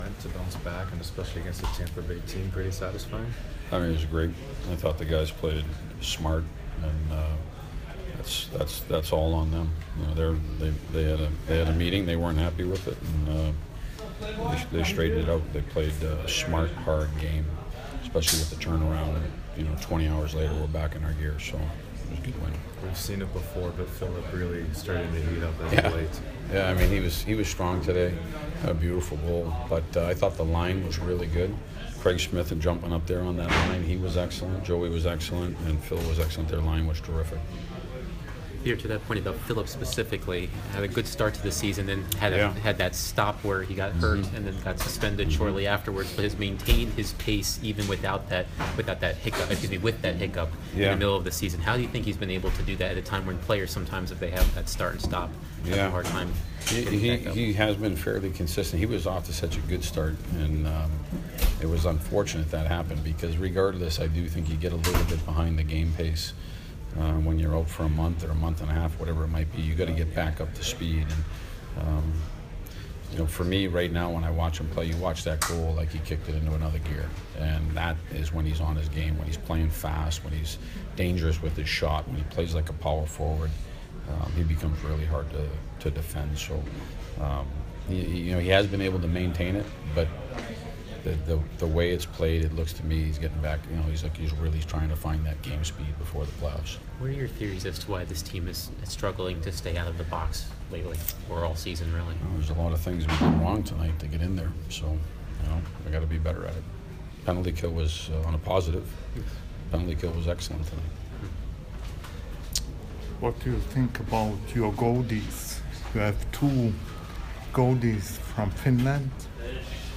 Nashville head coach Peter Laviolette post-game 4/1